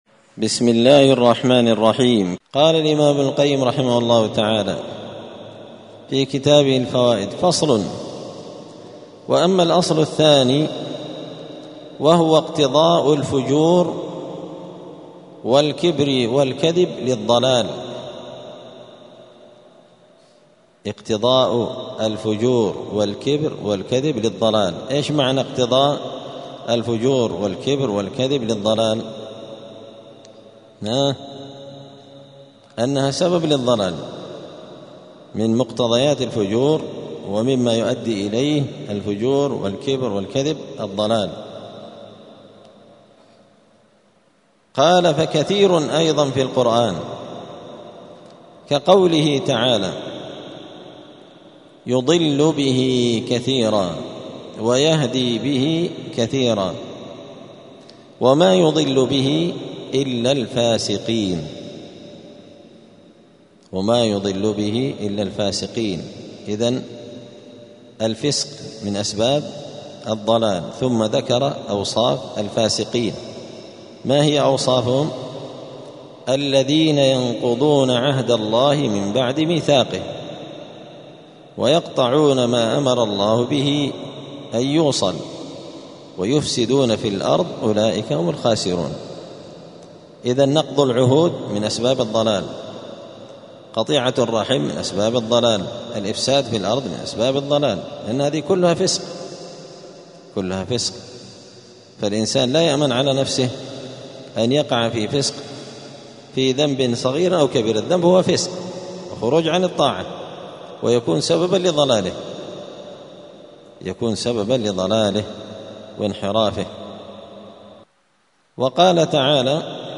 *الدرس الواحد والسبعون (71) {فصل: اقتضاء الفجور والكبر والكذب للضلال}*